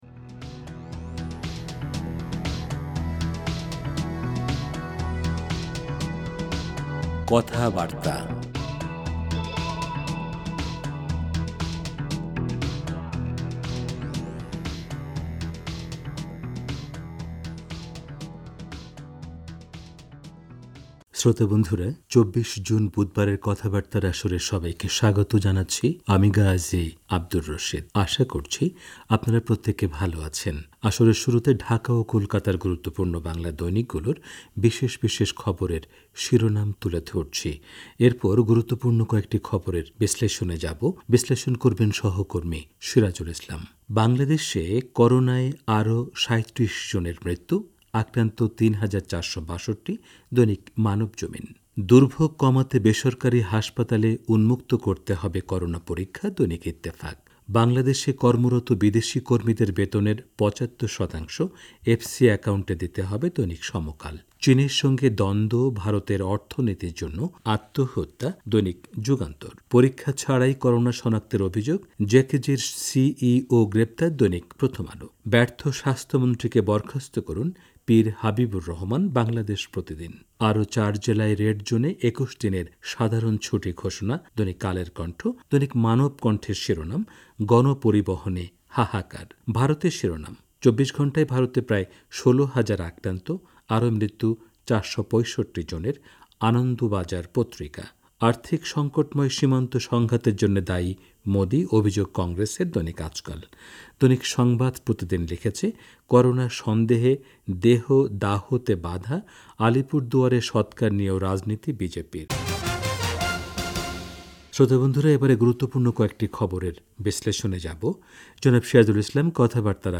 আসরের শুরুতে ঢাকা ও কোলকাতার গুরুত্বপূর্ণ বাংলা দৈনিকগুলোর বিশেষ বিশেষ খবরের শিরোনাম তুলে ধরছি। এরপর গুরুত্বপূর্ণ কয়েকটি খবরের বিশ্লেষণে যাবো।